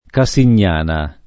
Casignana [ ka S in’n’ # na ] top. (Cal.)